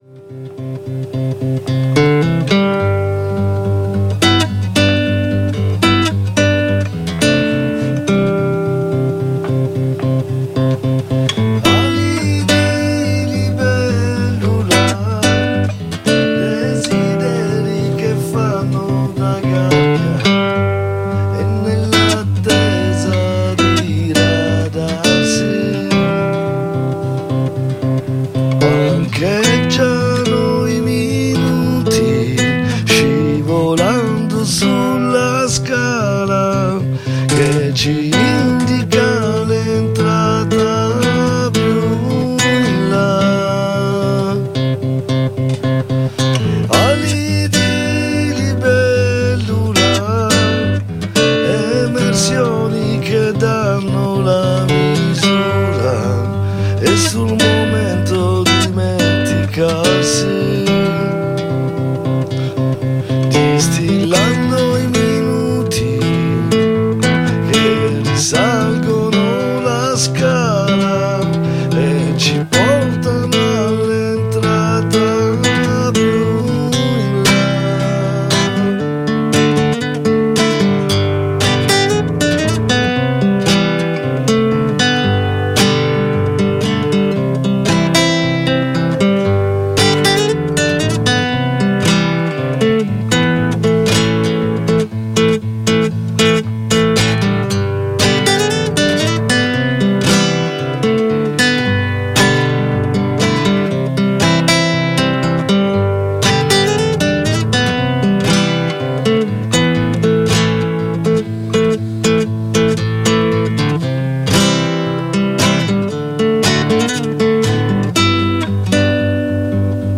Live
dal vivo
Un disco intenso, fresco e corroborante.